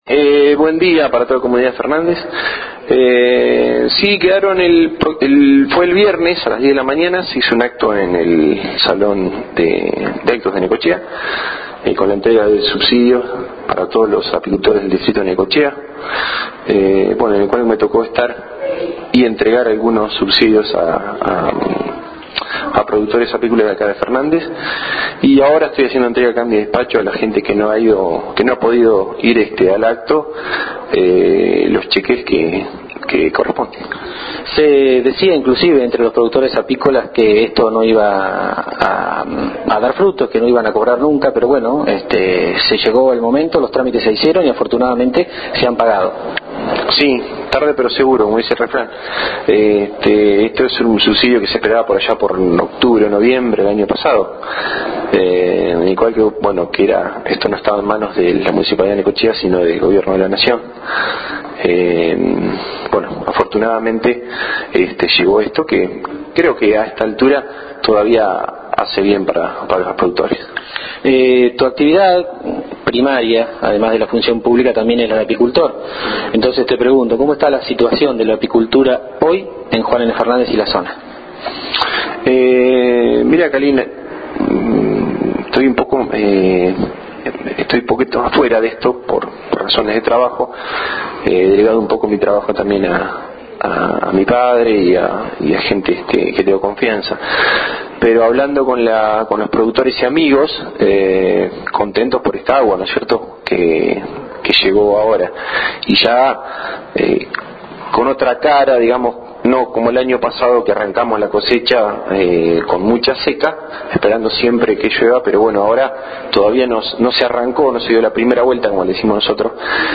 Lo dijo en diálogo exclusivo con JNFNet, en referencia a aquellos productores que no han abonado la aplicación del insecticida contra la tucura.
Escuchar audio del Delegado Municipal Juan José Van der Vluet